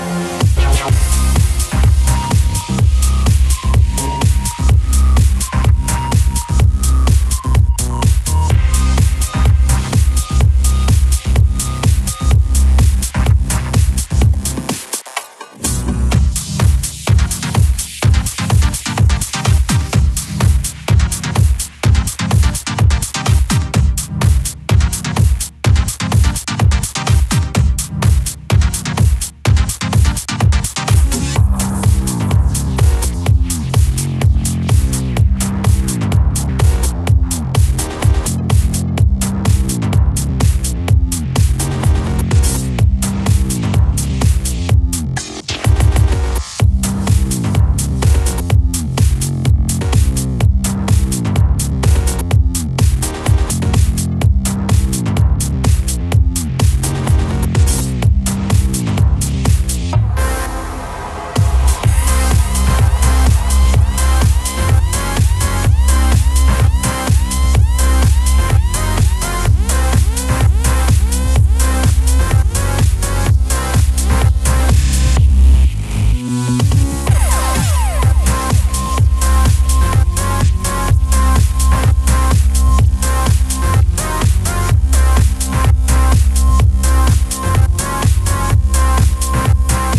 ジャンル(スタイル) DEEP HOUSE / HOUSE / TECHNO